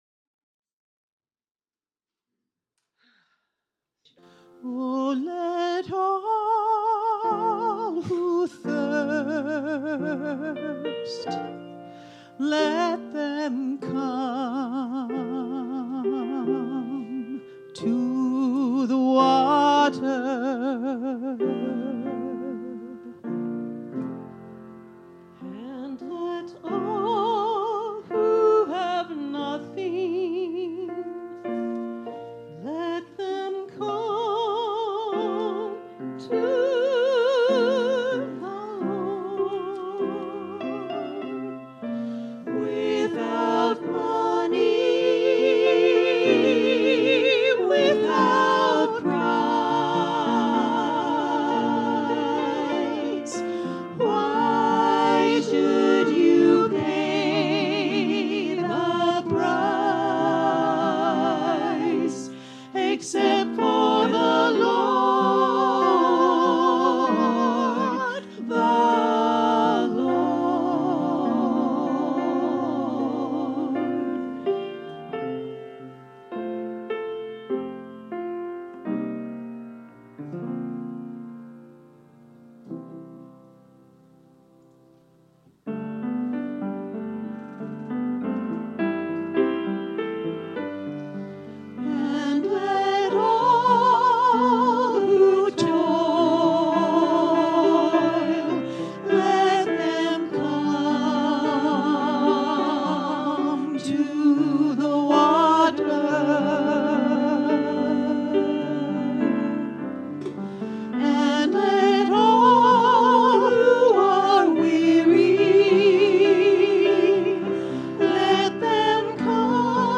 The audio recording (below the video clip) is an abbreviation of the service. It includes the Featured Song, Message, and Meditation.